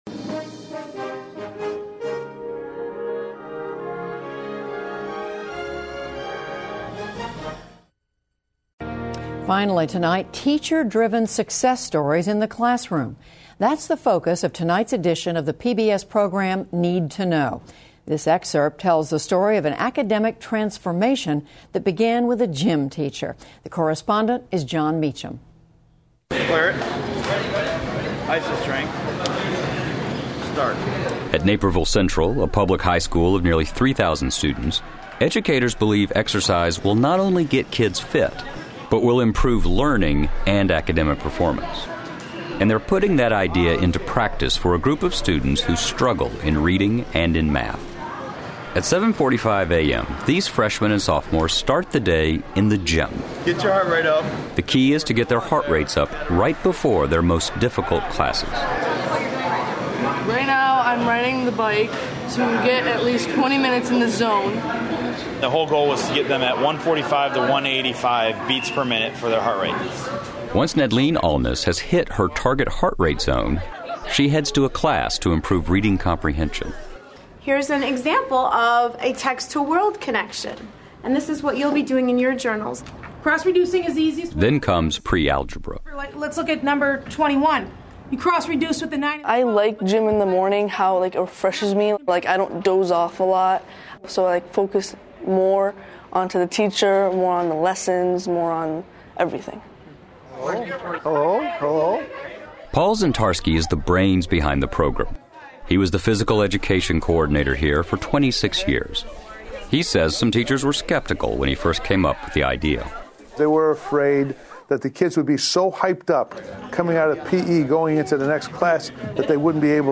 pbs高端访谈:体育课帮助学生提高成绩 听力文件下载—在线英语听力室